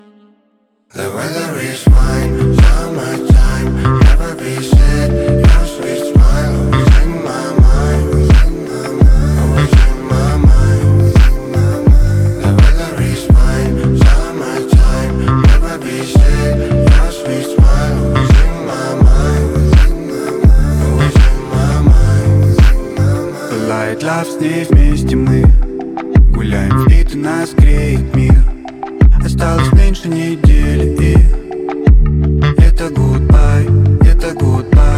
Urbano latino Latin